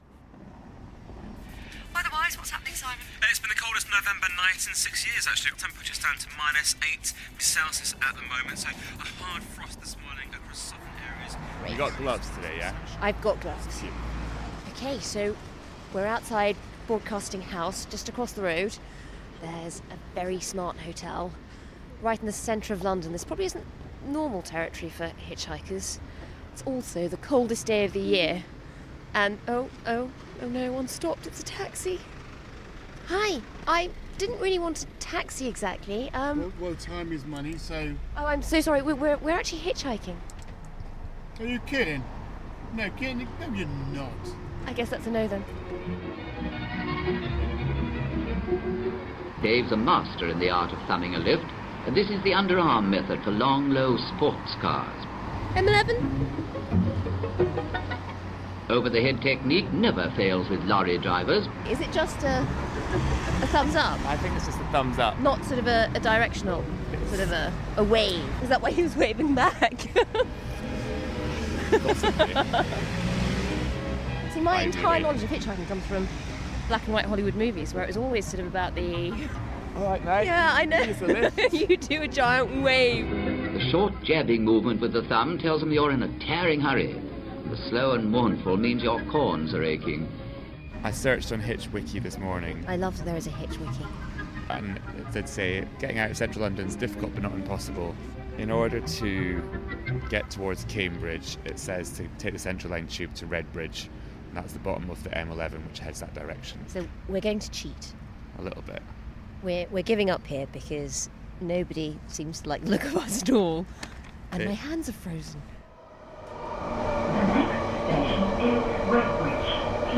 First broadcast on BBC Radio 4's iPM on 10th December 2016.